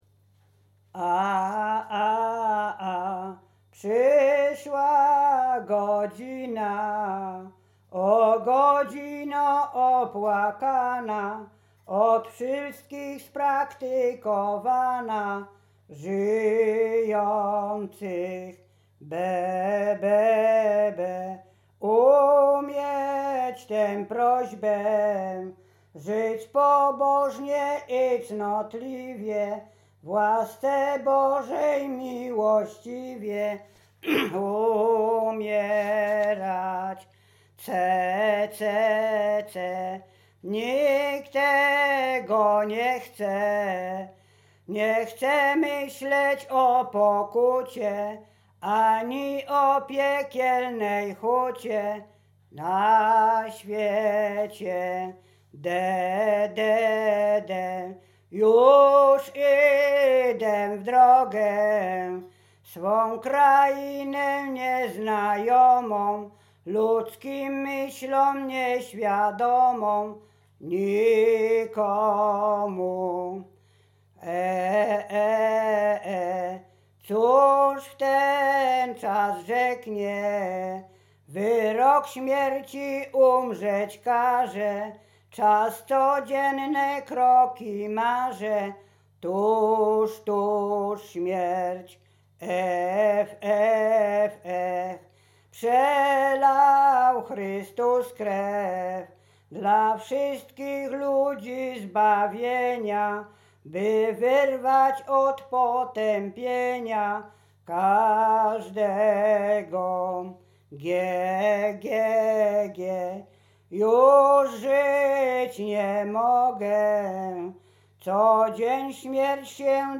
Pogrzebowa
pogrzebowe nabożne katolickie do grobu